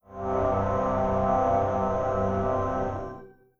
Samsung Galaxy S60 Startup.wav